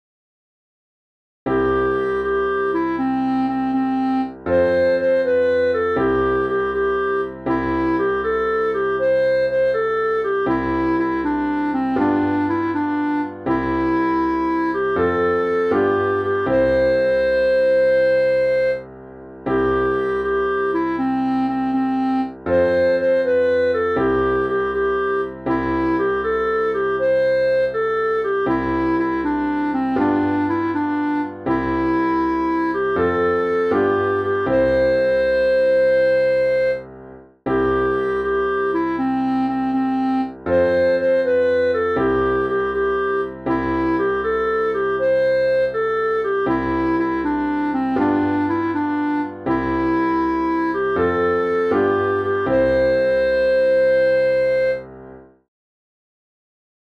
Ohayou no Uta (Cançó tradicional del Japó)
Interpretació musical de la cançó tradicional del Japó